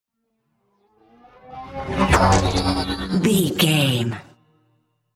Pass by fast vehicle sci fi
Sound Effects
futuristic
pass by
vehicle